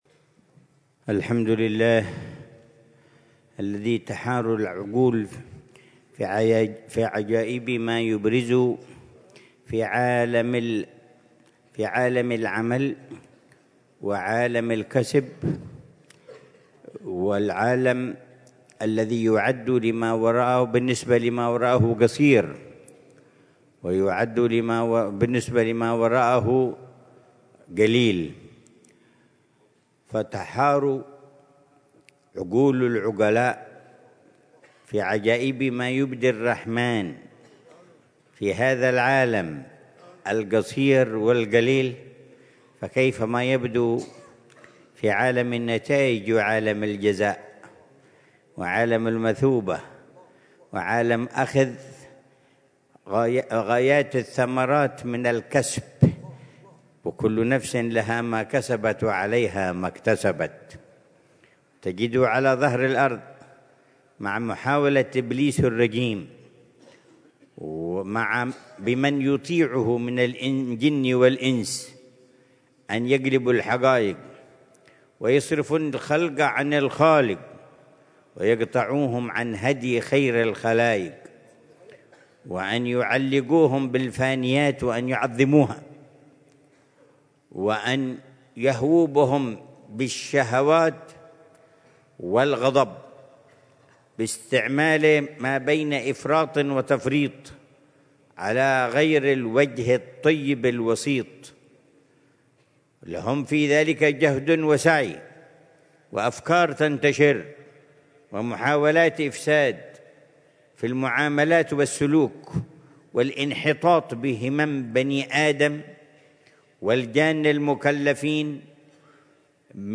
محاضرة العلامة الحبيب عمر بن محمد بن حفيظ ضمن سلسلة إرشادات السلوك في دار المصطفى، ليلة الجمعة 24 رجب الأصب 1446هـ، بعنوان: